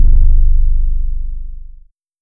STERNUM BASS.wav